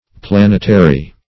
Planetary \Plan"et*a*ry\, a. [Cf. L. planetarius an astrologer,